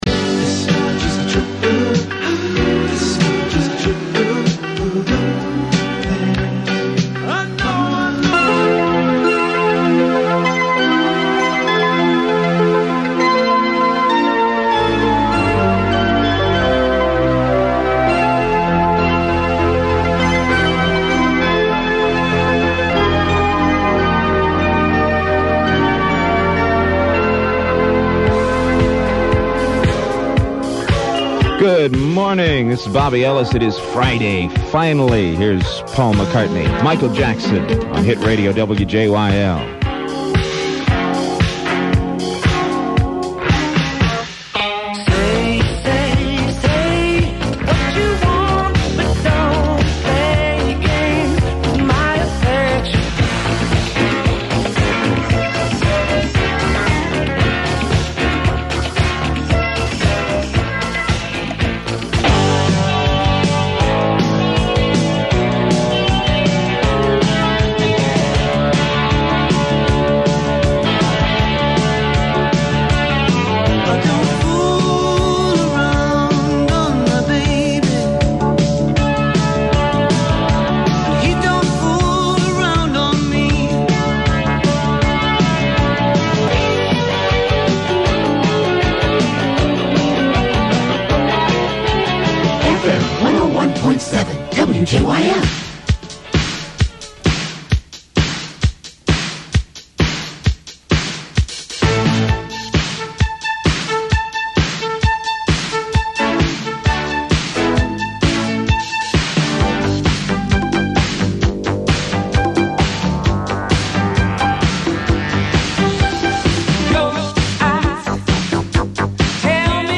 WJYL Airchecks